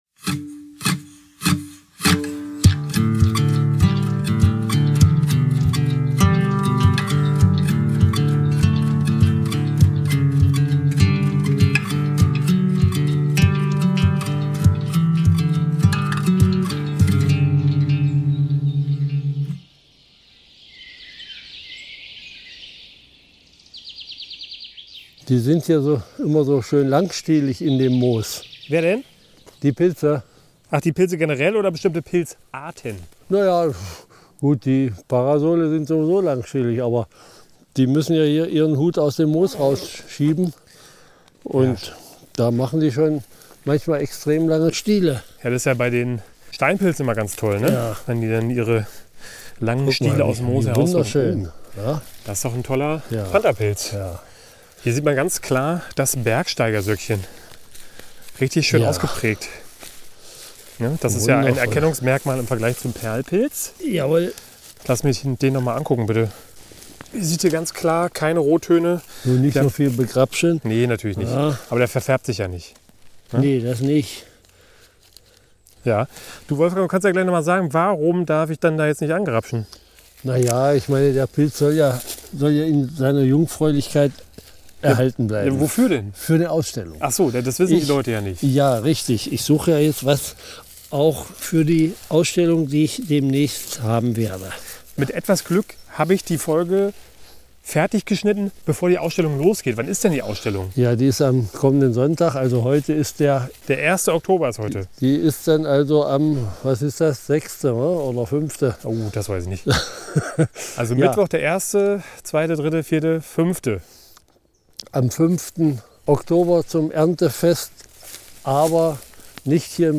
Für diese Folge sind wir in den Kiefernwald gefahren, weil die Trockenheit der letzten Wochen den Pilzen dort nicht ganz so viel anhaben kann, da sie im Moos etwas geschützter stehen, so unsere Vermutung.